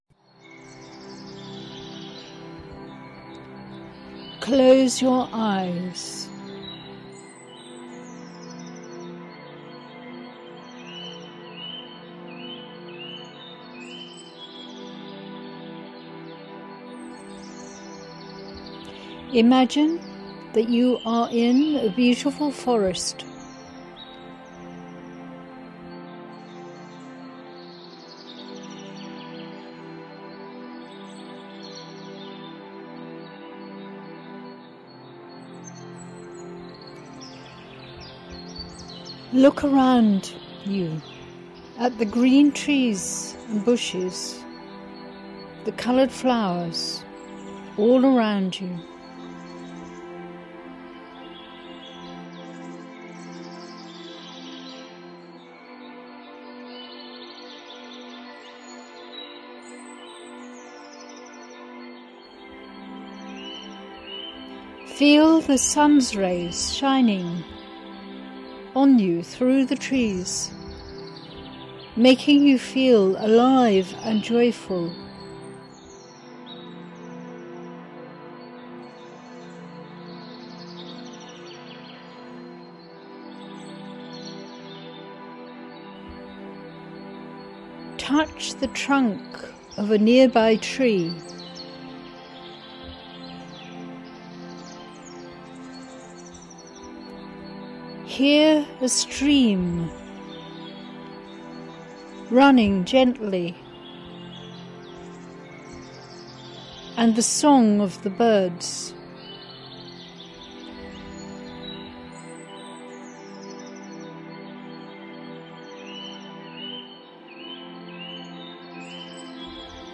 2 FREE GUIDED MEDITATIONS